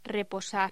Locución: Reposar
Sonidos: Voz humana